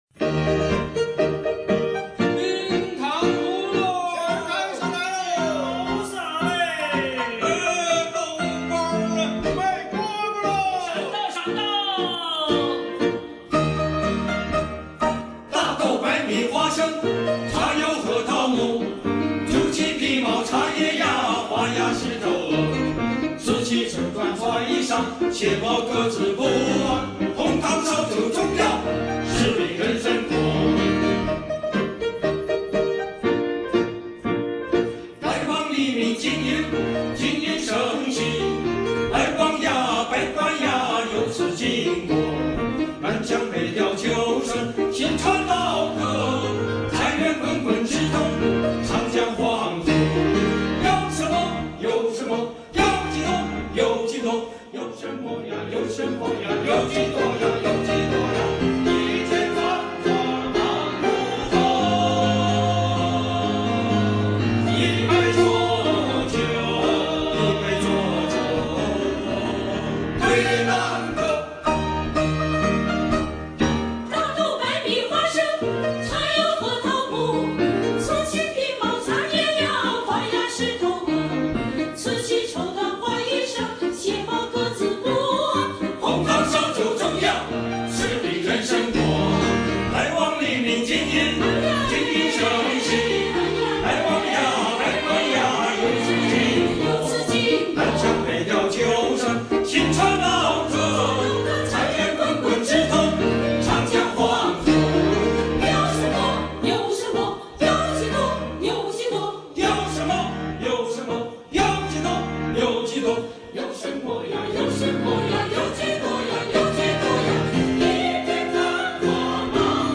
【夏季音樂會】秋韻組合：大豆百米花生--秋韻組合